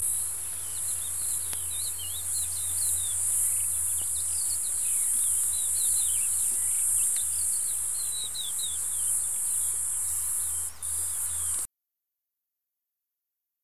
Cigale noire Cicadatra atra